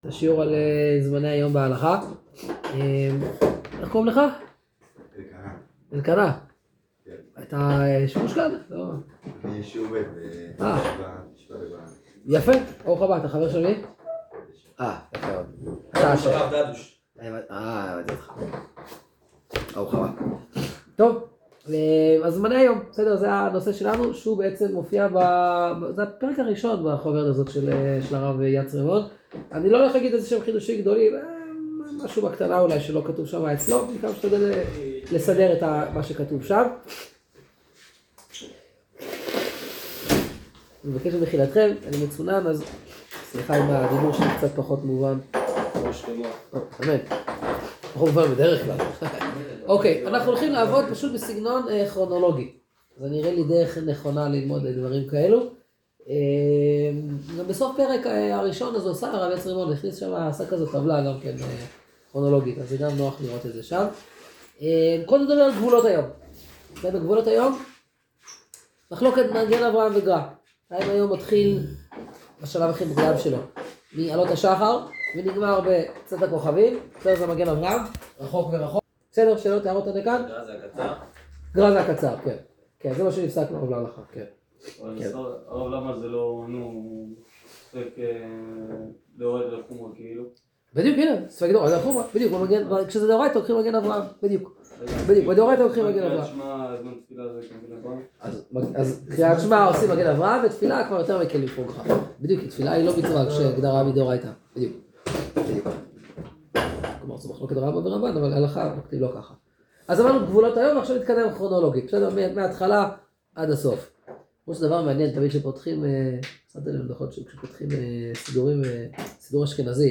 השיעור נקטע קצת בהתחלה